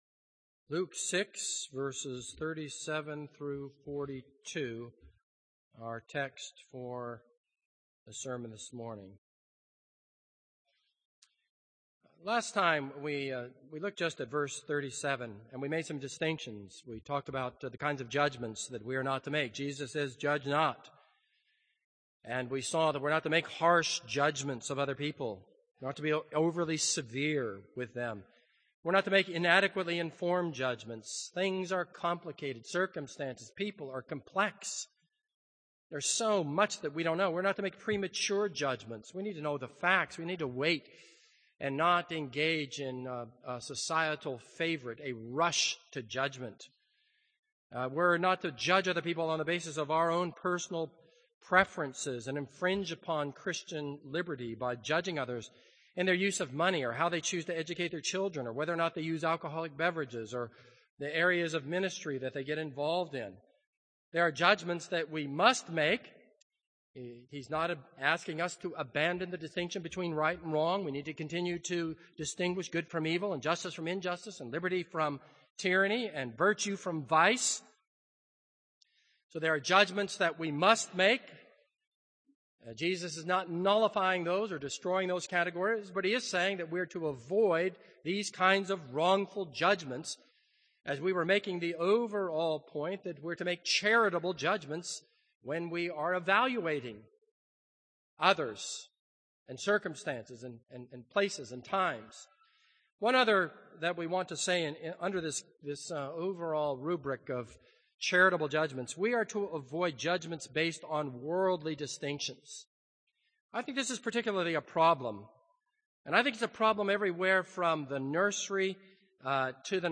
This is a sermon on Luke 6:37-42.